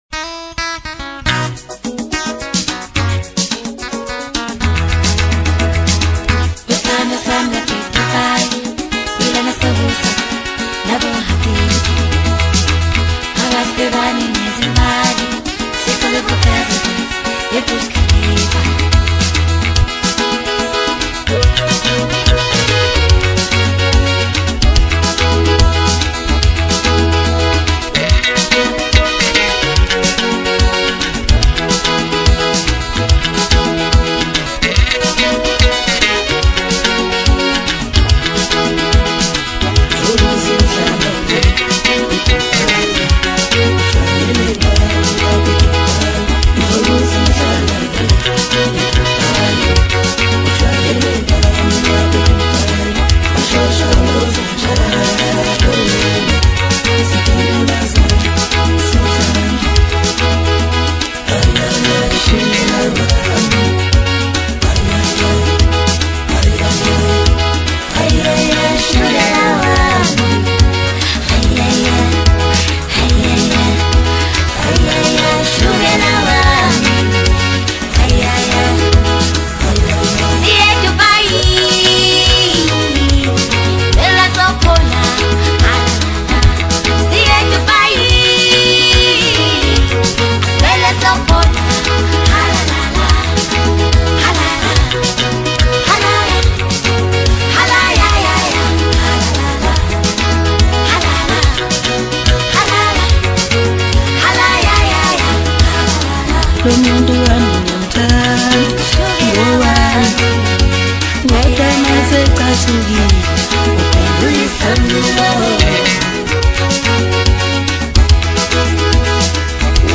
Home » Maskandi » Amapiano